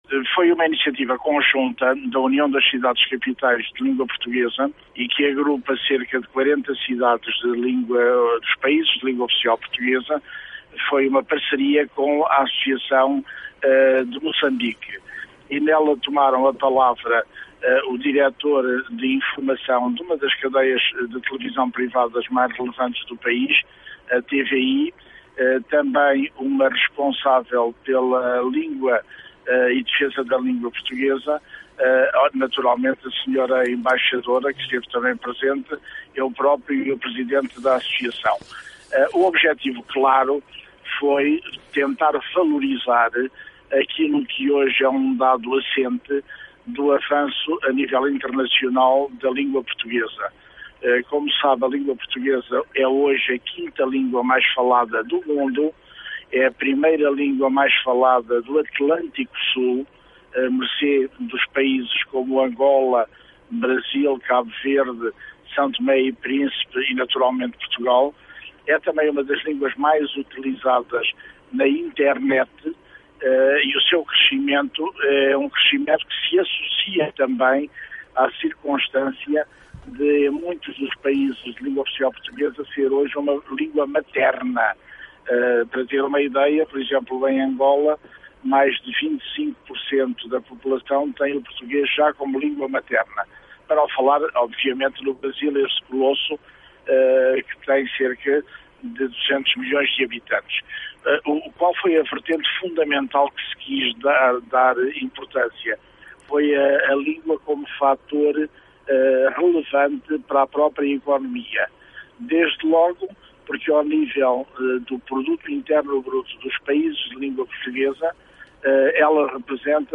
A União das Cidades Capitais de Língua Portuguesa levou a cabo na Casa de Moçambique em Lisboa um debate sobre “ A Língua como Bandeira Económica”.